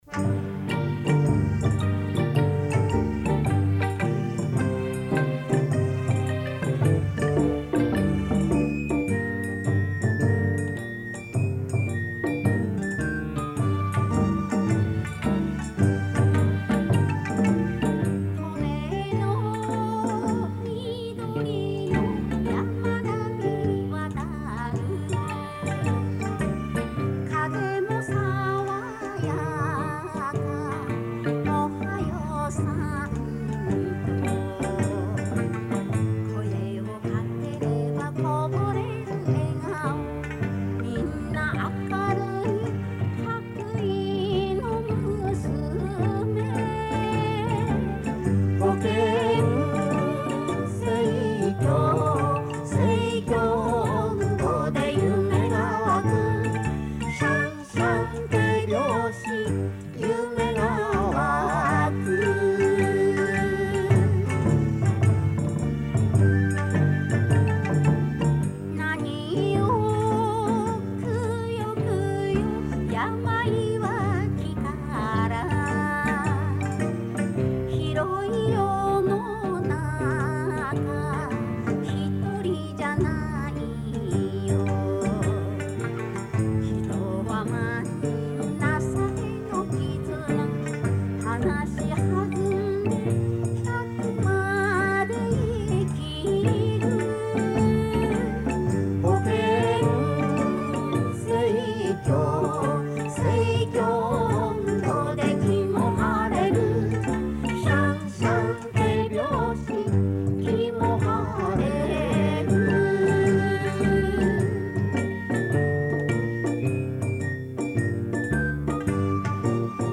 tone-hoken-seikyo-ondo.mp3